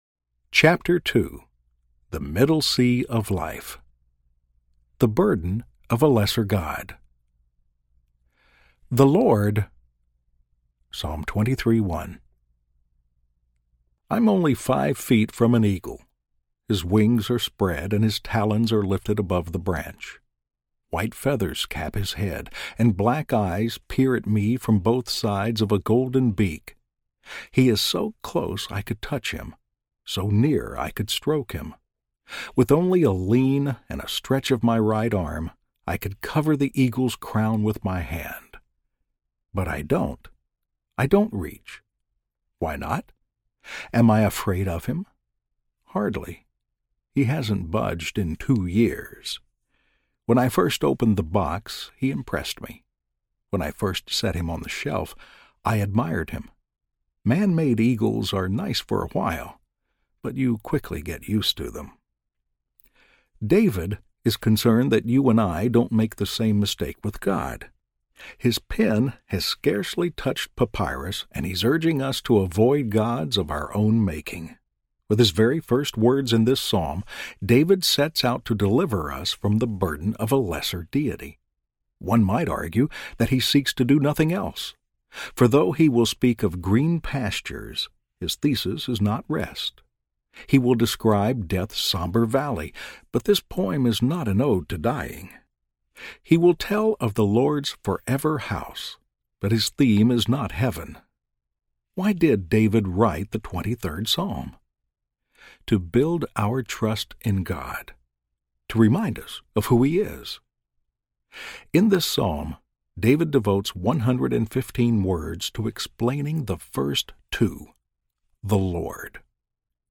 Traveling Light Audiobook